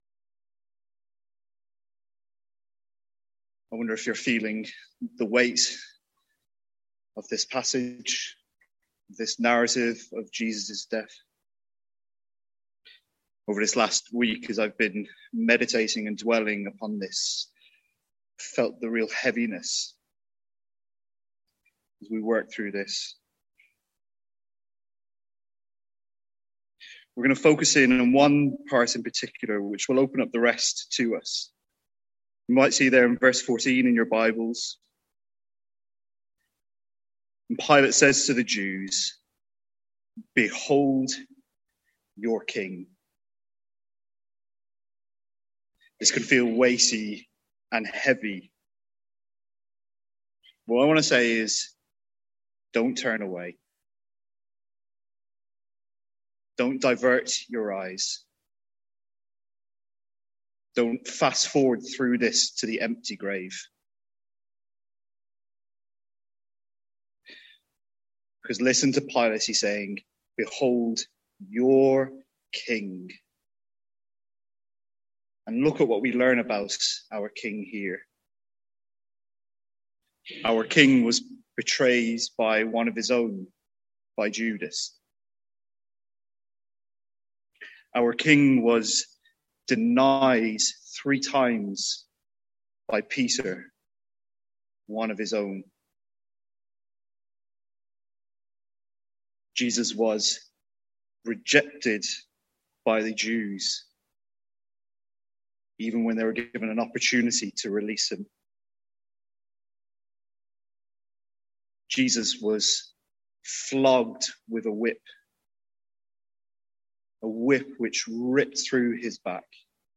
Sermons | St Andrews Free Church
From our Good Friday Service.